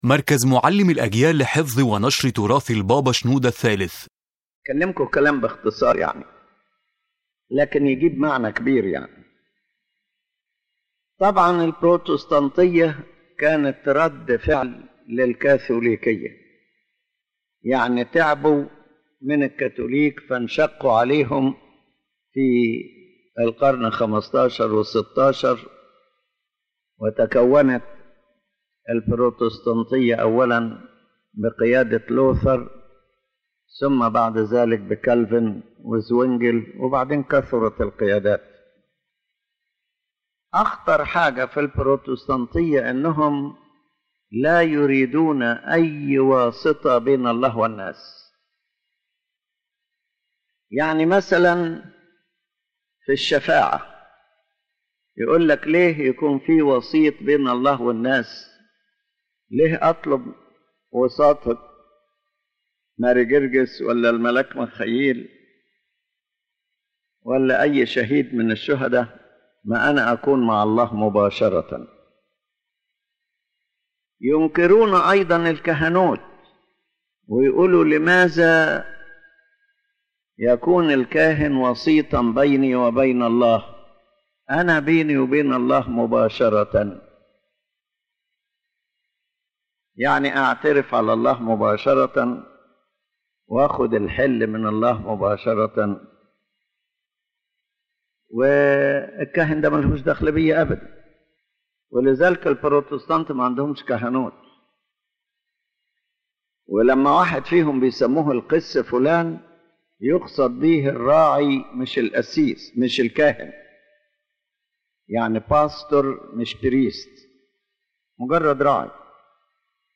The lecture addresses the essence of the dispute between the Coptic Orthodox tradition and the Protestant tradition, focusing on three main points: the Protestants’ rejection of ecclesiastical mediations (intercessions, priesthood, and sacraments), the concept of salvation by faith alone versus the role of works and grace, and the Church’s role as a mediator and as the instrument through which the Holy Spirit conveys sacraments and grace to people.